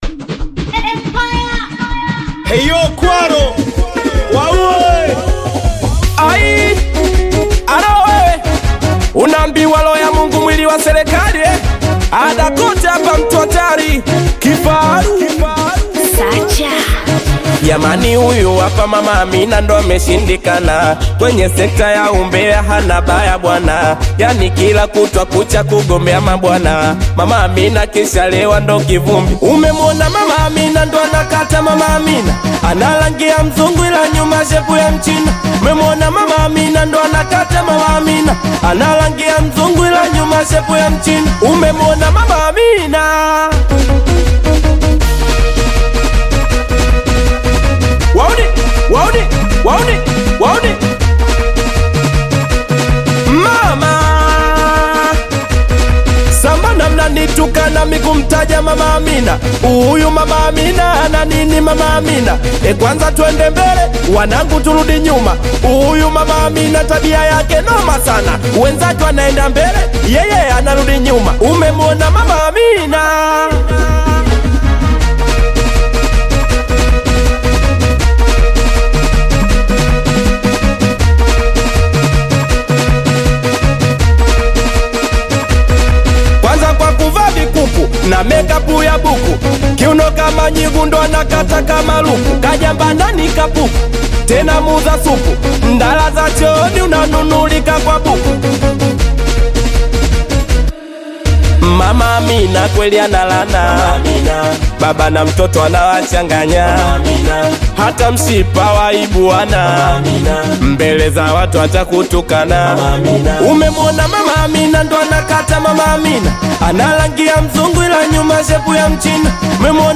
Singeli music track
Singeli